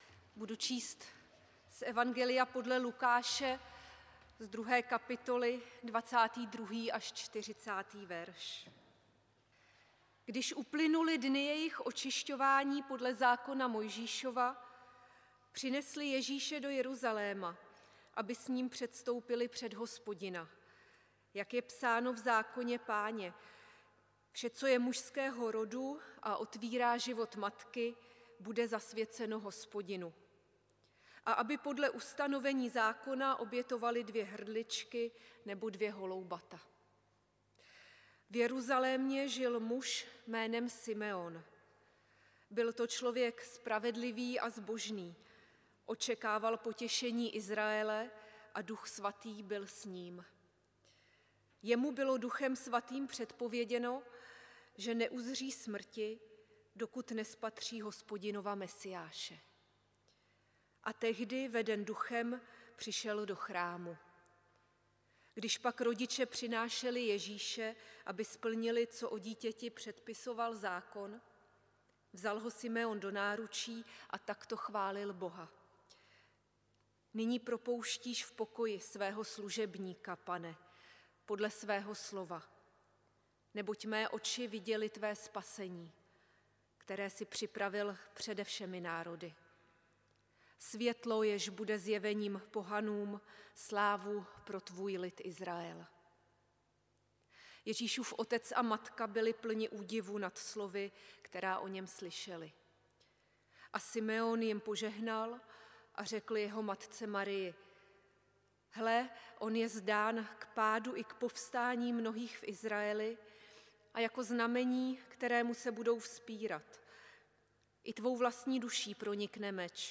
Bohoslužby s Večeří Páně 2. 2. 2025 • Farní sbor ČCE Plzeň - západní sbor